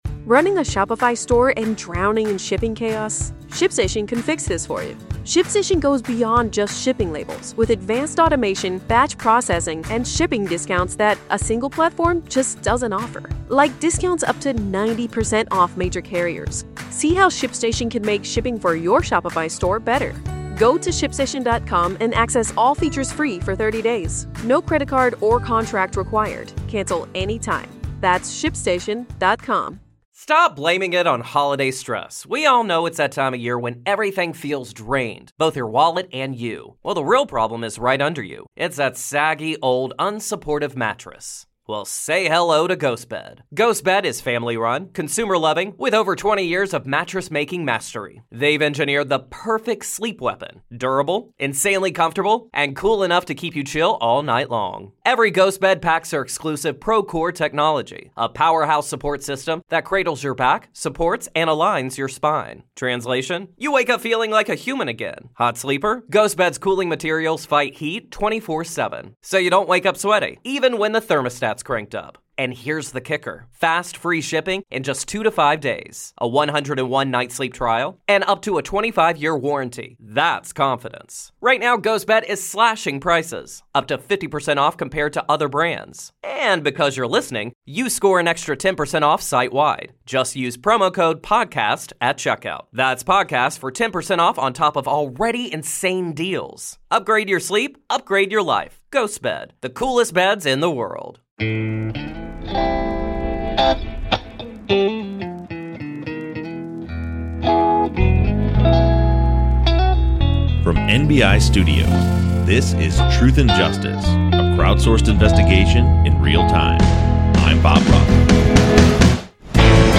This episode will be a read-along of the Probable Cause Affidavit